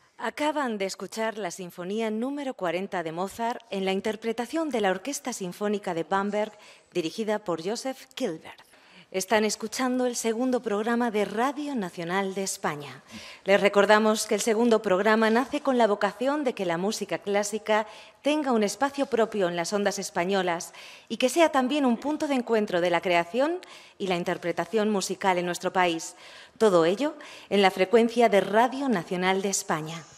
Recreació del primer dia d'emissió del Segundo Programa de RNE amb abast Estatal. Tema musical que s'ha escoltat, identificació